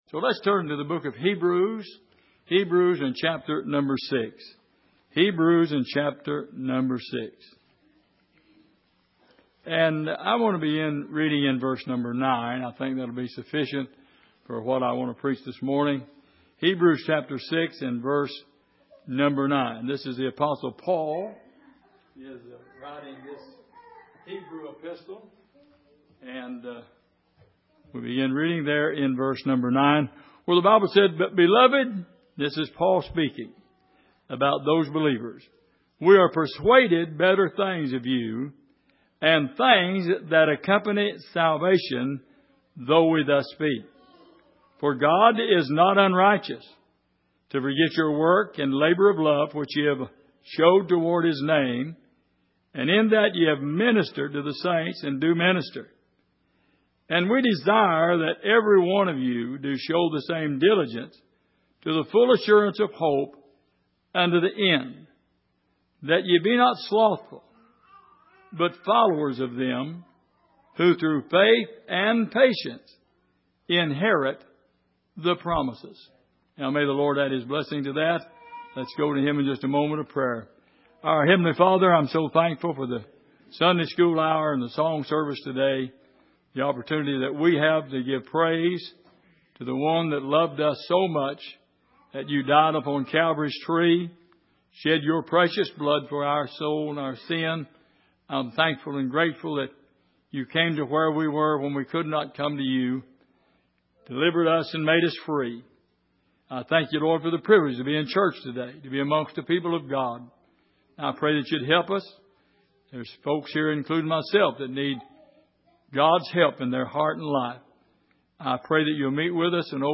Passage: Hebrews 6:9-12 Service: Sunday Morning